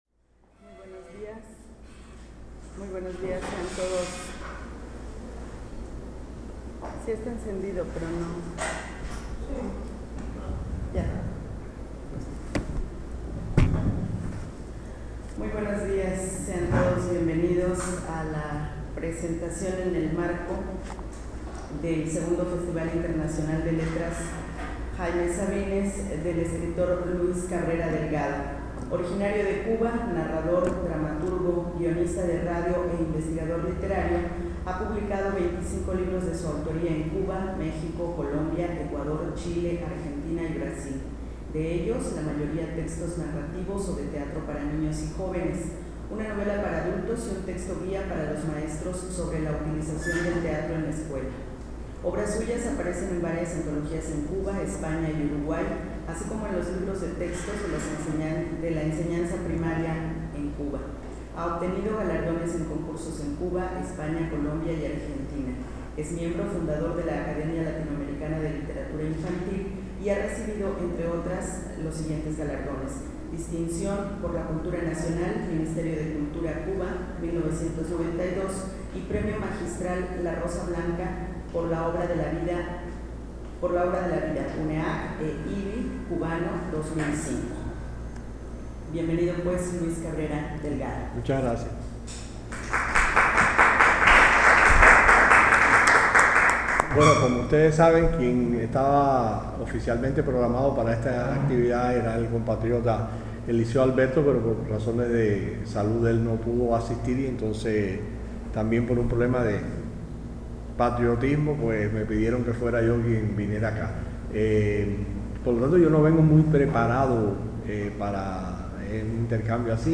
Lugar: Auditorio del Campus Humanidades de la Universidad Autónoma de Chiapas. Equipo: iPod 2Gb con iTalk Fecha: 2008-11-02 07:11:00 Regresar al índice principal | Acerca de Archivosonoro